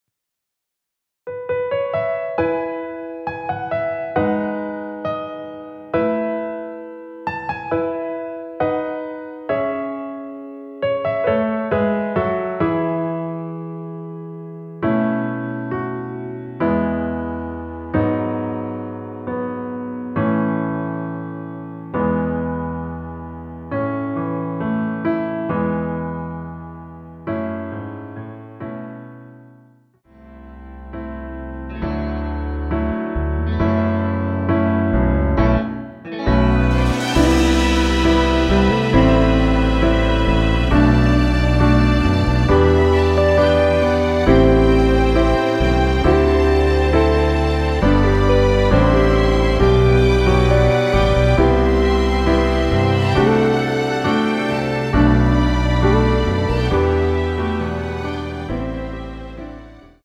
원키에서(+2)올린 MR입니다.
◈ 곡명 옆 (-1)은 반음 내림, (+1)은 반음 올림 입니다.
앞부분30초, 뒷부분30초씩 편집해서 올려 드리고 있습니다.
중간에 음이 끈어지고 다시 나오는 이유는